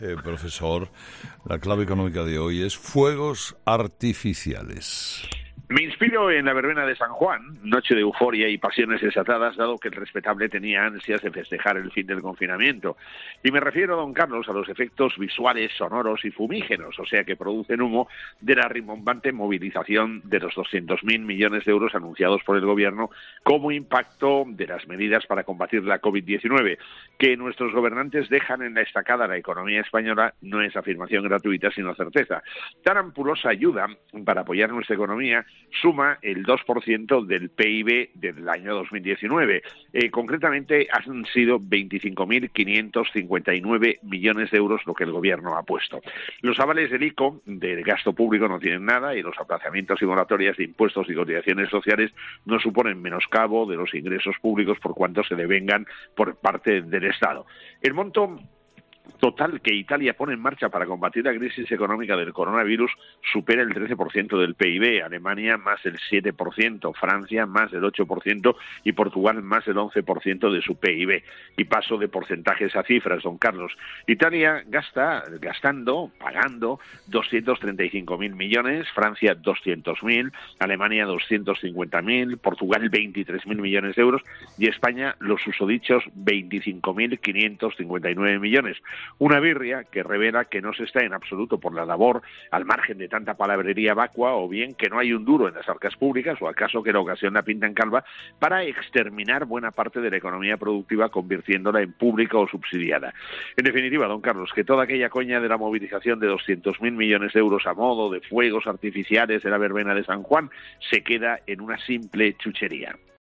El profesor José María Gay de Liébana analiza en ‘Herrera en COPE’ las claves económicas del día